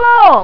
I used "microcomputer control mode" to play each of the 8 samples while capturing the data at the two PWM outputs, then converted them to WAV format: Sound 0 Sound 1